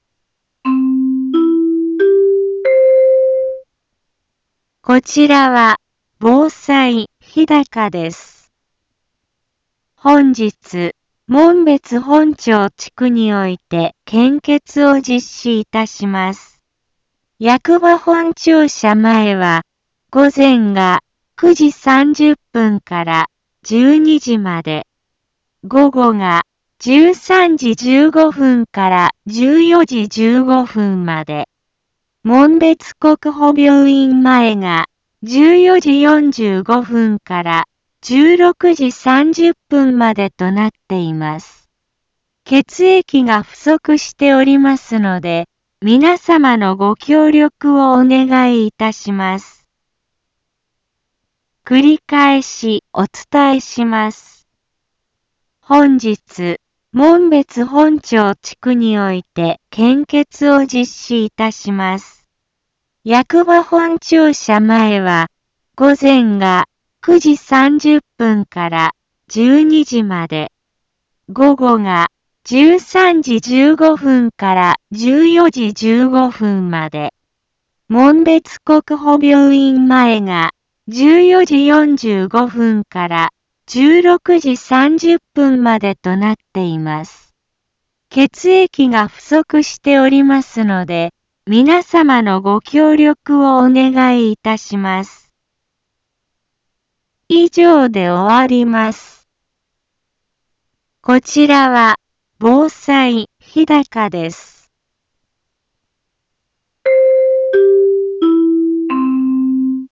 一般放送情報
Back Home 一般放送情報 音声放送 再生 一般放送情報 登録日時：2020-05-29 10:03:52 タイトル：献血のお知らせ インフォメーション：こちらは、防災日高です。